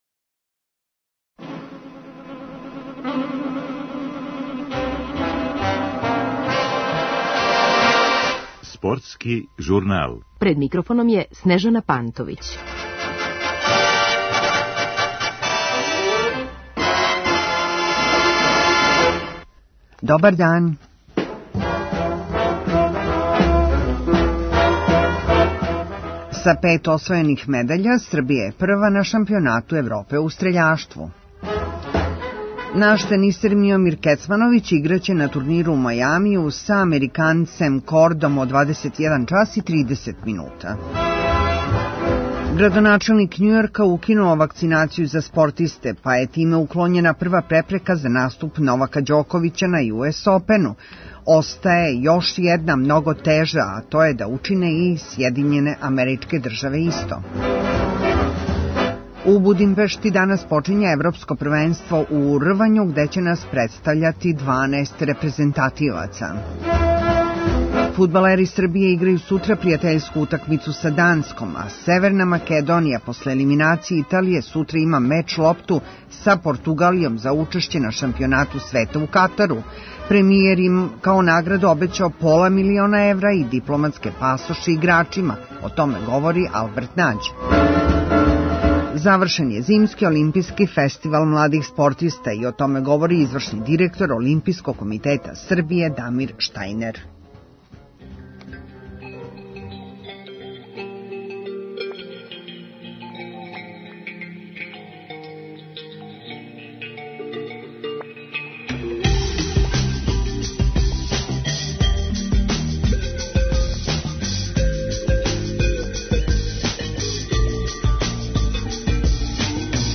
О томеће говорити бивши фудбалски ас Албеерт Нађ.